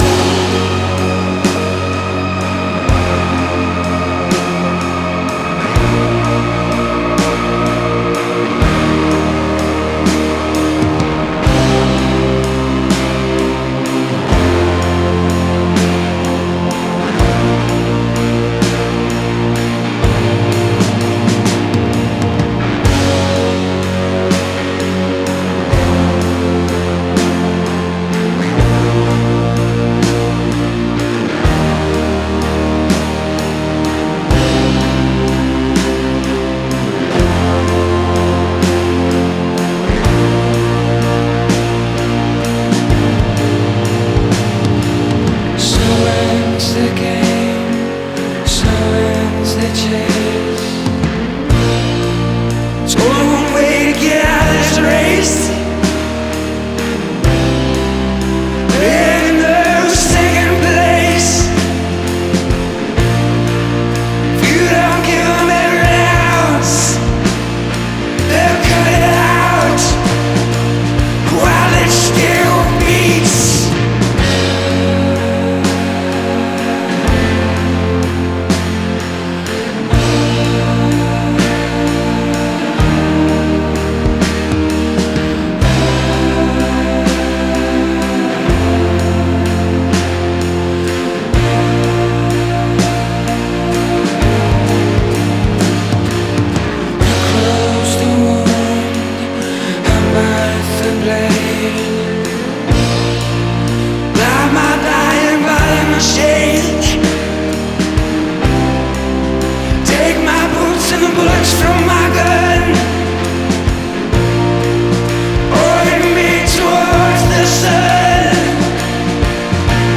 Genre: Folk Rock, Singer-Songwriter